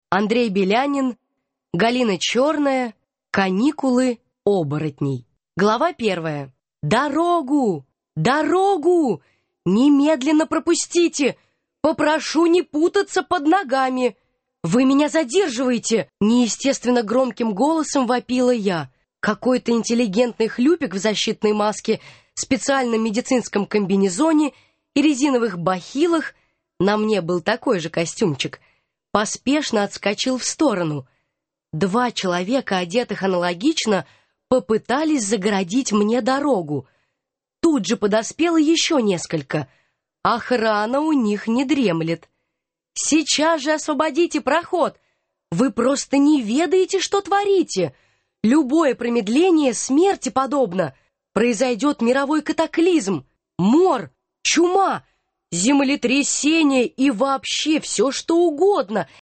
Аудиокнига Каникулы оборотней | Библиотека аудиокниг